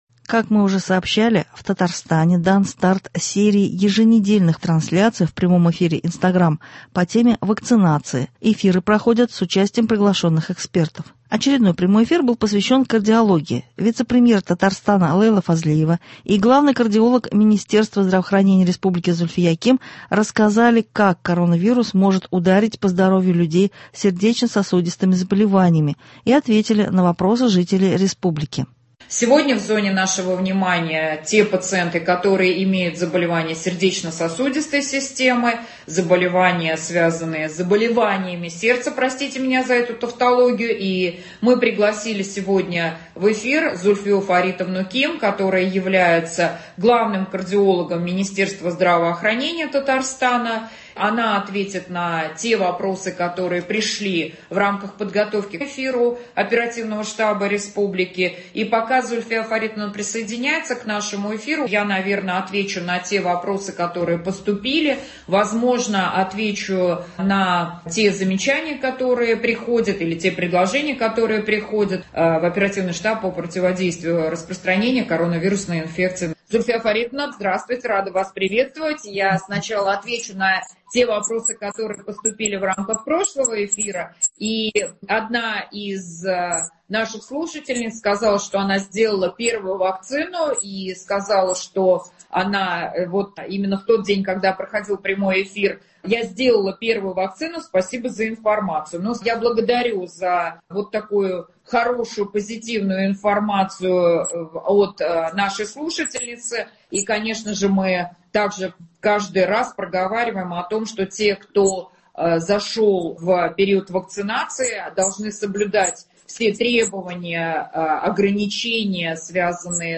Как мы уже сообщали, в Татарстане Дан старт серии еженедельных прямых трансляций в прямом эфире Инстаграм по теме вакцинации с участием приглашенных экспертов.
Очередной прямой эфир был посвящен кардиологии.